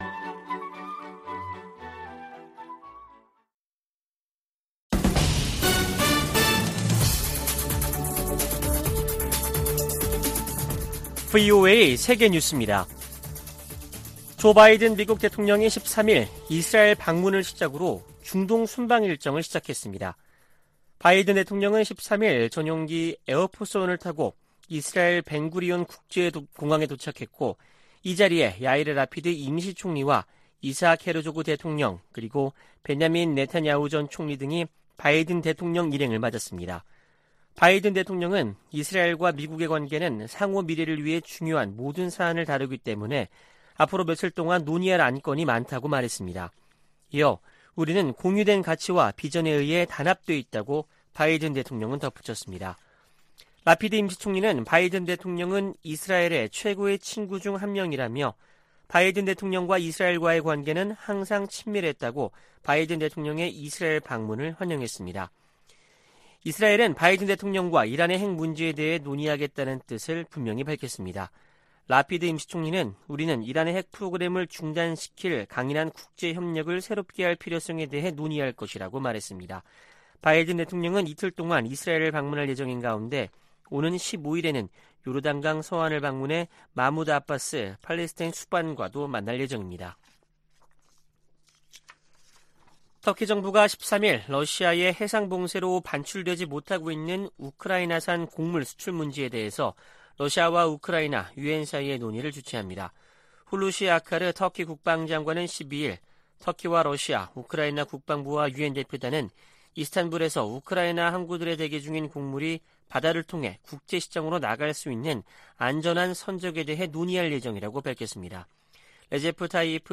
VOA 한국어 아침 뉴스 프로그램 '워싱턴 뉴스 광장' 2022년 7월 14일 방송입니다. 전임 도널드 트럼프 미국 행정정부에서 고위 관료를 지낸 인사들이 강력한 대북 제재로, 김정은 국무위원장이 비핵화의 길로 나오도록 압박해야 한다고 말했습니다. 미 국무부 선임고문이 한국 고위 당국자들을 만나 양국 관계 강화와 국제 현안 협력 방안을 논의했습니다. 북한이 최근 방사포를 발사한 것과 관련해 주한미군은 강력한 미한 연합방위태세를 유지하고 있다고 밝혔습니다.